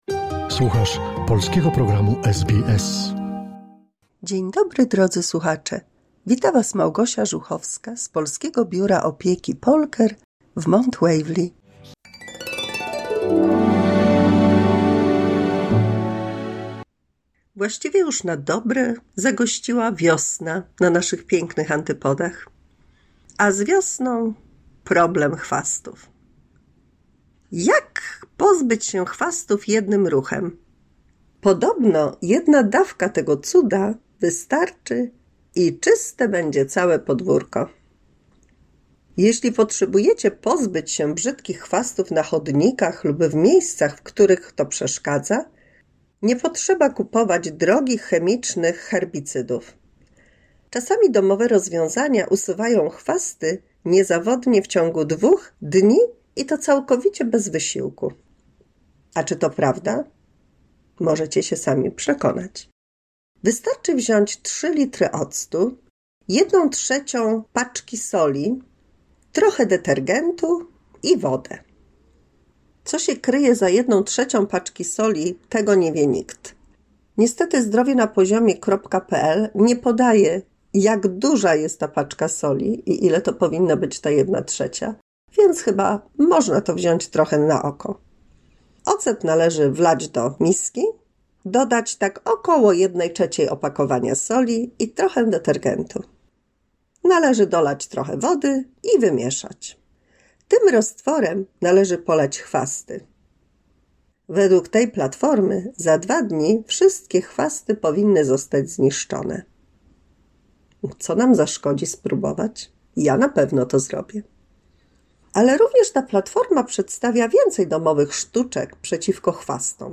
W 223 mini-słuchowisku dla polskich seniorów rady jak pozbyć się niechcianych chwastów oraz legenda o powstaniu Kaszub..